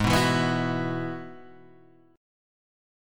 G#m7#5 Chord